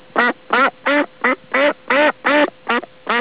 Papera
papera.wav